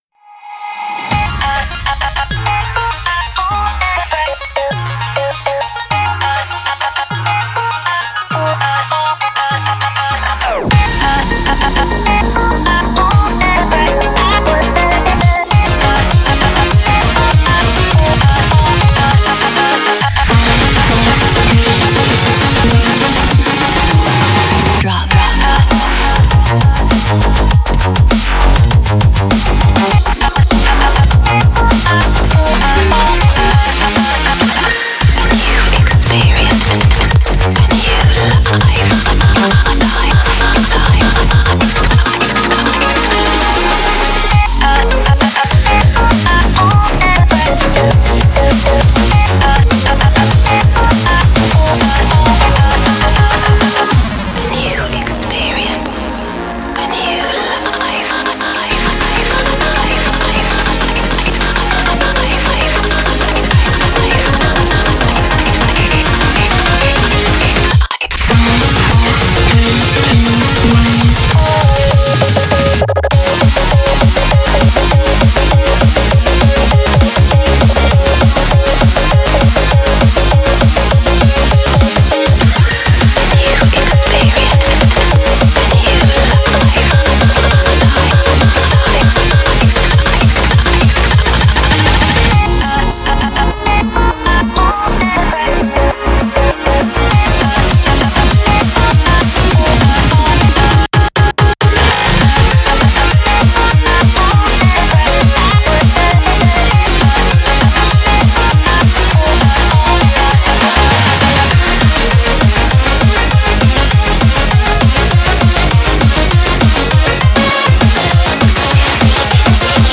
BPM100-200
Audio QualityPerfect (High Quality)
futuristic